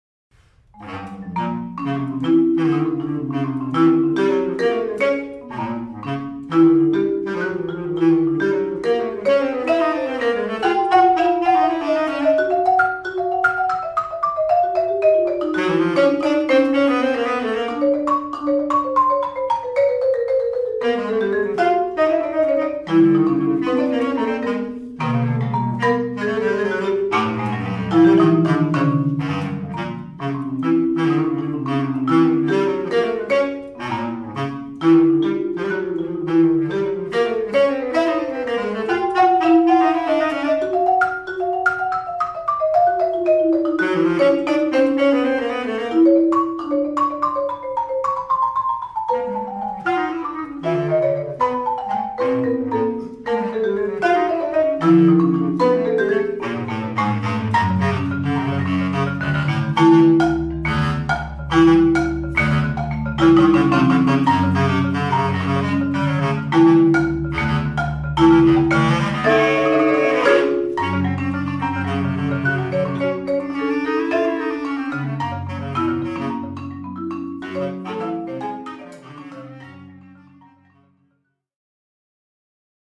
Instrumentation: B-flat bass clarinet and 5-octave marimba